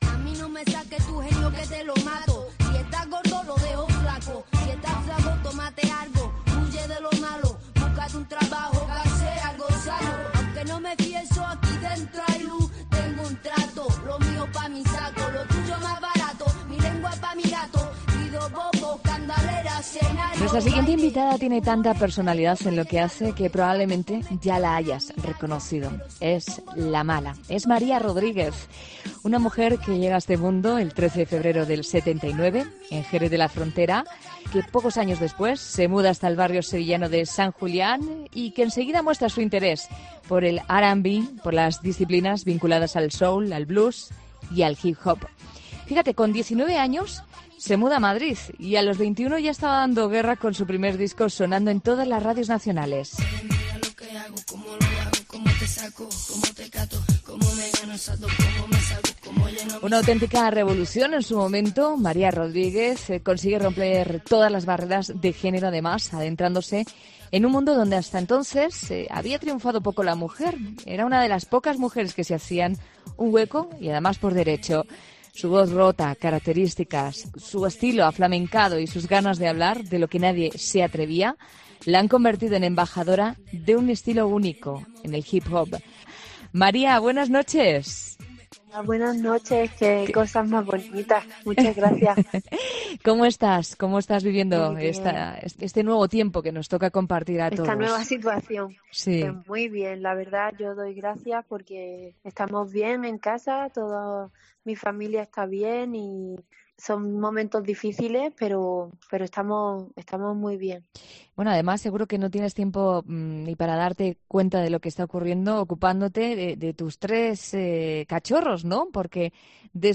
Su voz rota tan característica, su estilo aflamencado y sus ganas de hablar de lo que nadie se atrevía la convirtieron en la embajadora de un estilo único de hip hop.